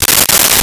Wrapper Tear
Wrapper Tear.wav